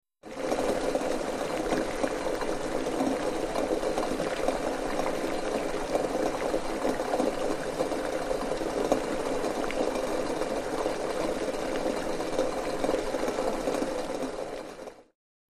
Boiling Pot 2; Water Boiling Rapidly; Almost Tinny Sounding. Close Perspective. Kitchen, Restaurant.